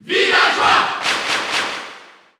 Villager_Male_Cheer_French_PAL_SSBU.ogg